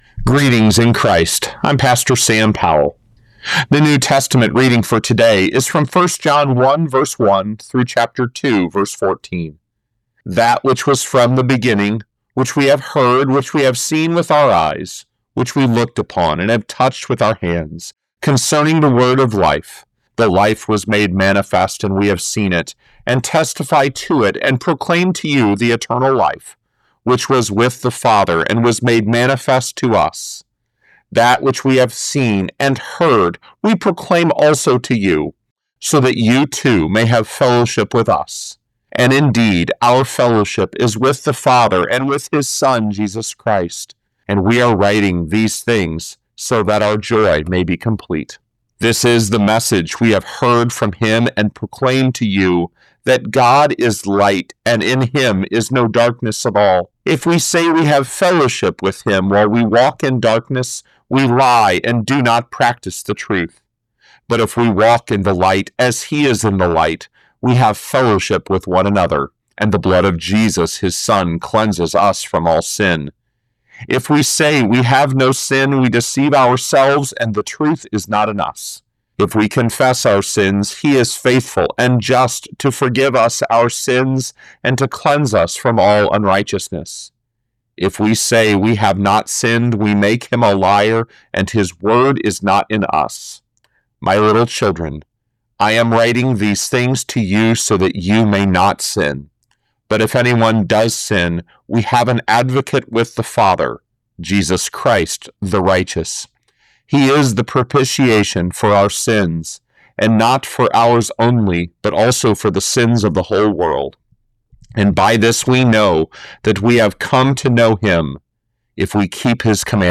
Morning Prayer Sermonette: 1 John 1:1-2:14
Hear a guest pastor give a short sermonette based on the day’s Daily Lectionary New Testament text during Morning and Evening Prayer.